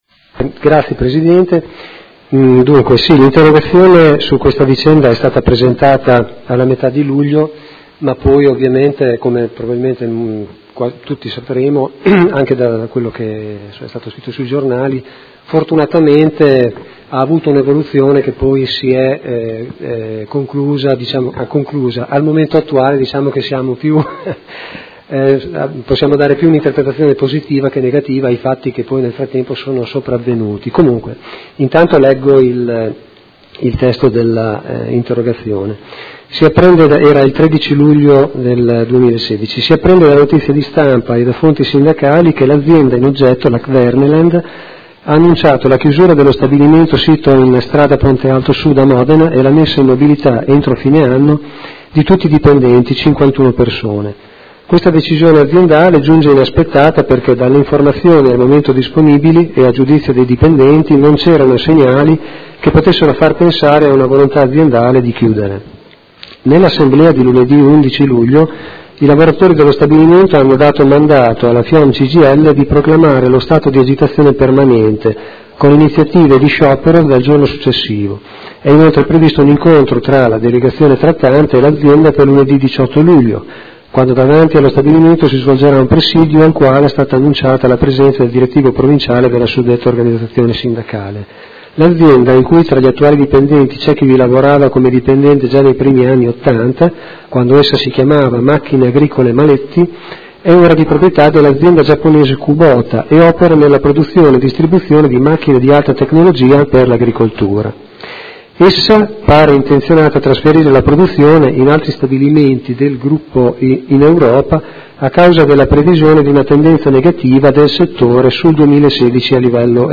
Seduta del 22/09/2016 Interrogazione dei Consiglieri Malferrari, Trande e Bortolamasi (PD) avente per oggetto: Chiusura stabilimento Kverneland